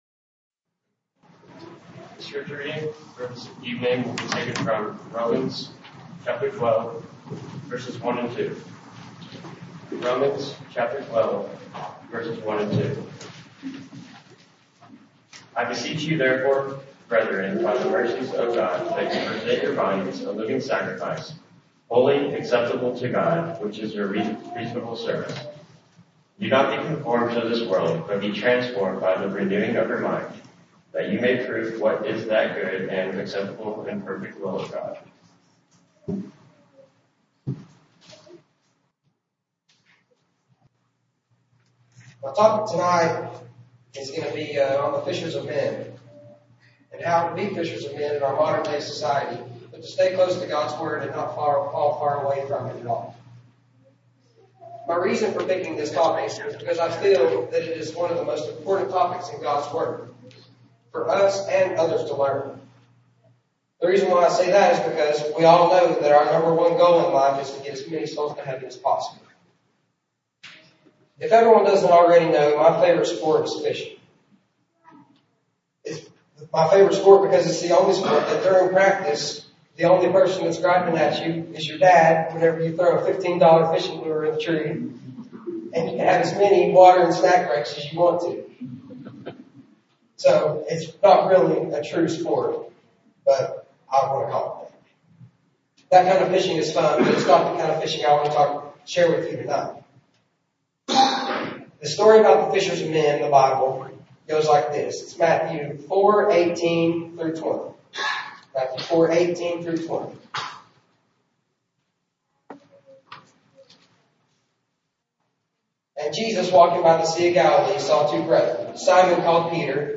Youth Service